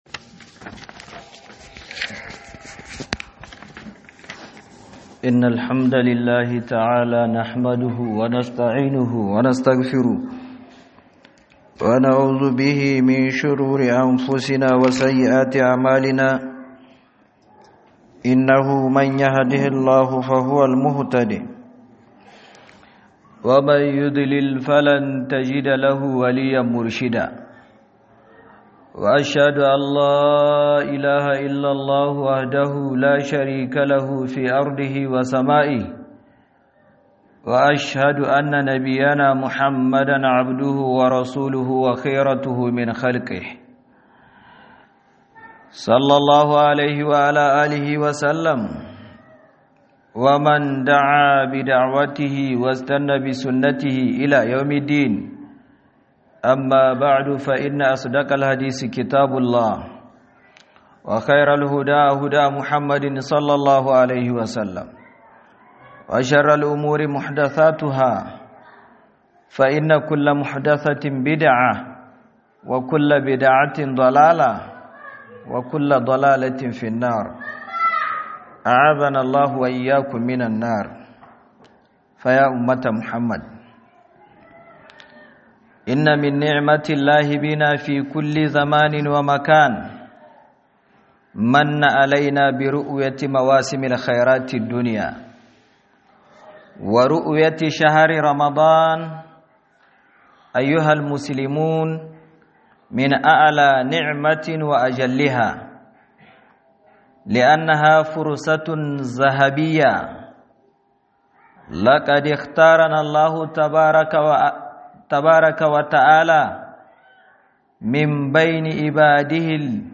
huduba 2026-02-20_12'46'48'